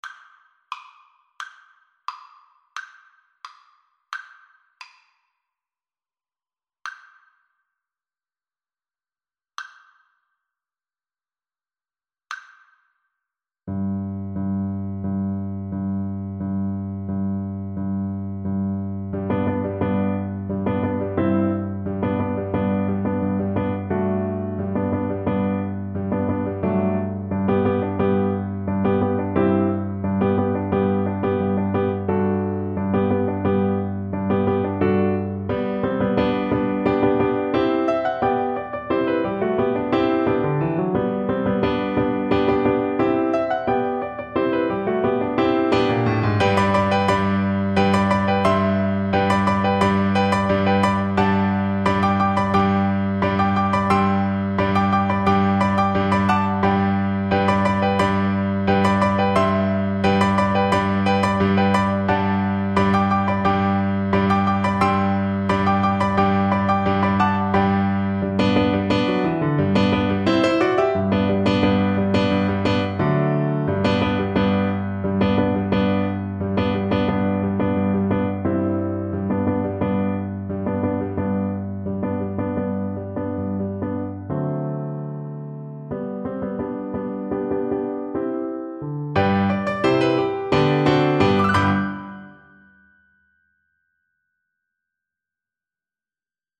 Play (or use space bar on your keyboard) Pause Music Playalong - Piano Accompaniment transpose reset tempo print settings full screen
Violin
Traditional Music of unknown author.
Allegro Energico = c.88 (View more music marked Allegro)
G4-G6
G major (Sounding Pitch) (View more G major Music for Violin )
2/2 (View more 2/2 Music)